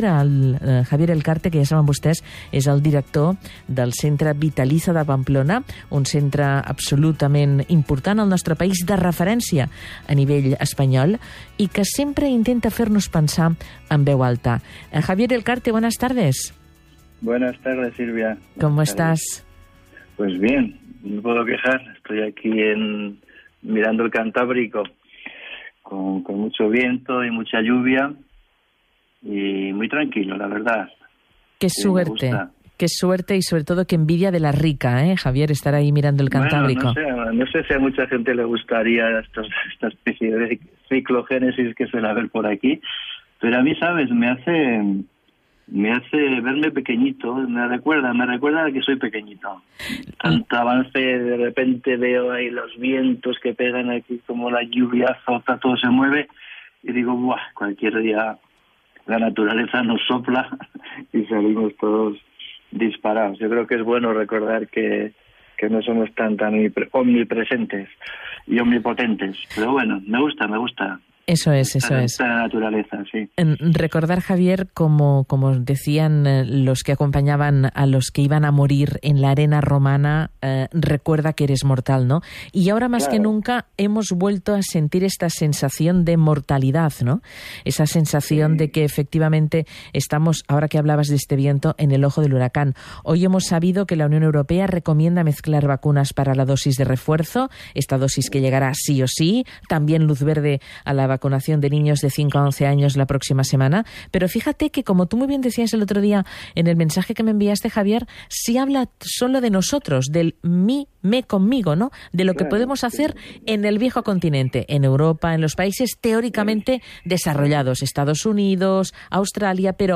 Escucha la entrevista completa en castellano dándole al play: